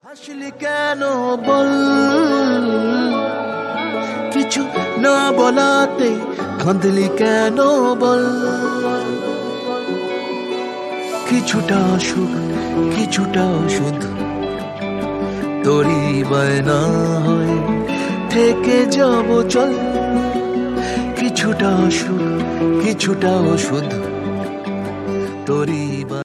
Bengali Romantic